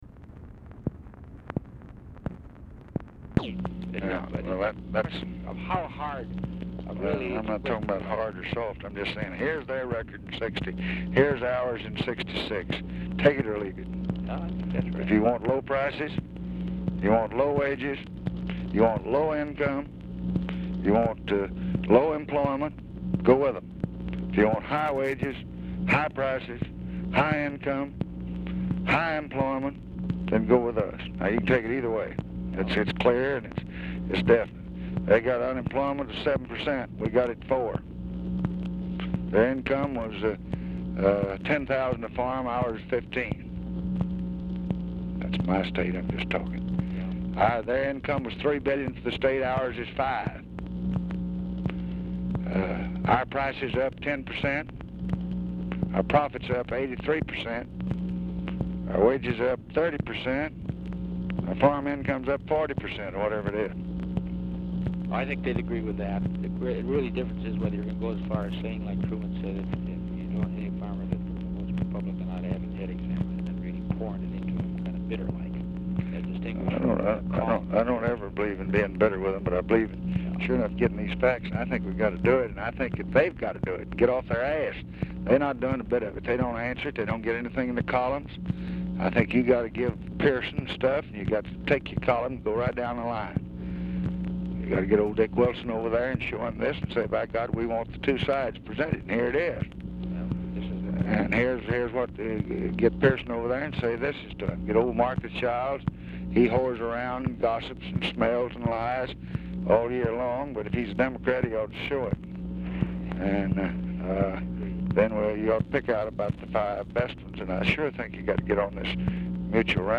Telephone conversation # 10769, sound recording, LBJ and ORVILLE FREEMAN, 9/14/1966, 9:55AM
Format Dictation belt
Location Of Speaker 1 Oval Office or unknown location